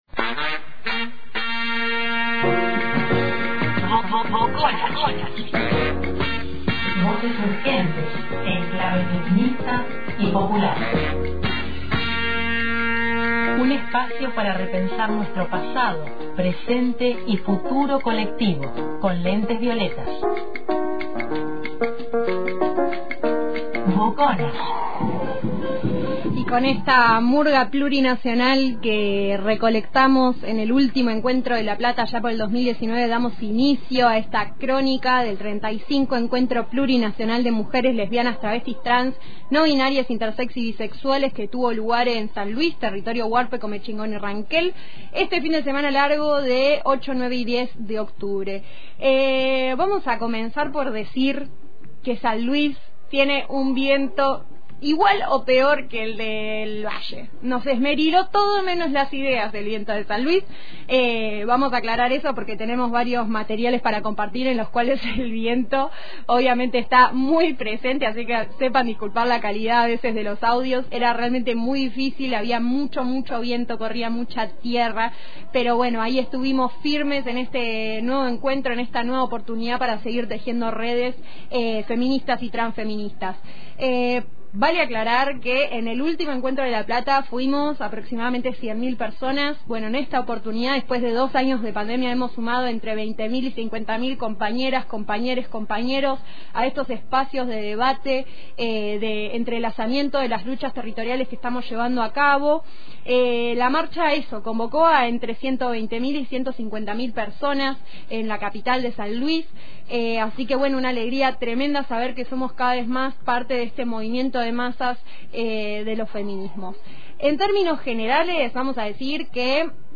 En esta edición de Boconas, voces urgentes en clave feminista y popular, compartimos la palabra de bienvenida de la comisión organizadora del 35º Encuentro Plurinacional de mujeres, lesbianas, travestis, trans, no binaries y bisexuales